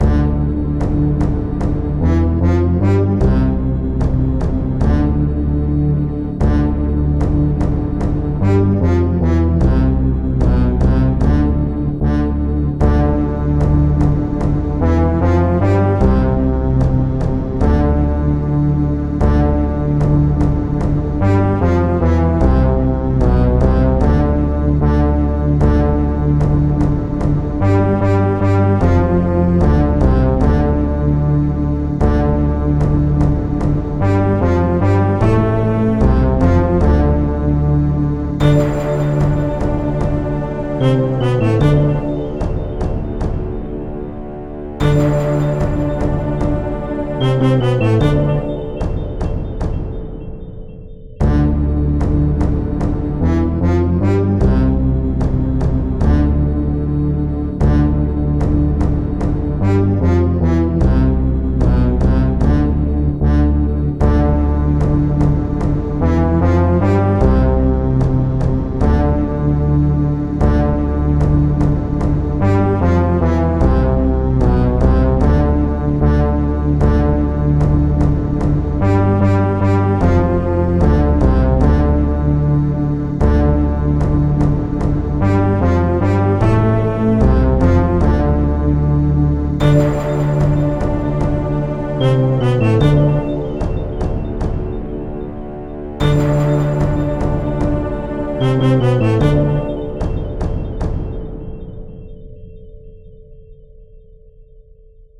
Synth Version